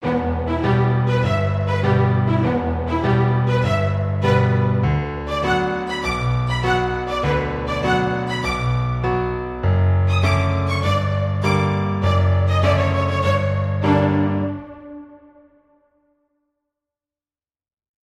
Piano
Strings
Fierce , Orchestral , Thriller , Aggressive